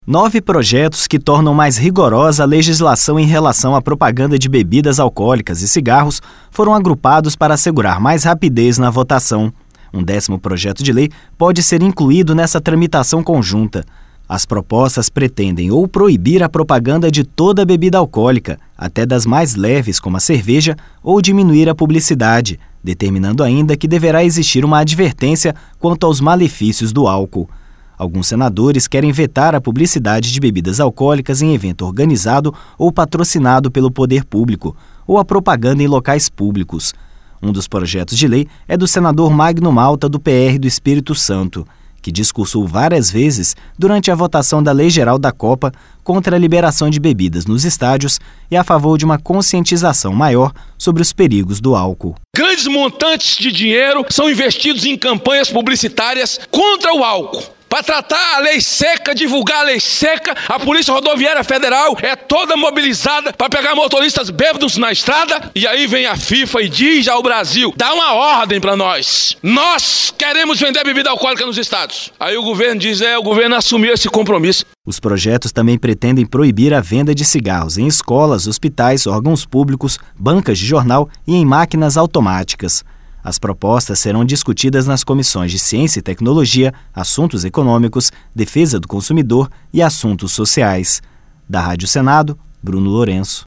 Um dos projetos de lei é do senador Magno Malta, do PR do Espírito Santo, que discursou várias vezes, durante a votação da Lei Geral da Copa, contra a liberação de bebidas nos estádios e a favor de uma conscientização maior sobre os perigos do álcool.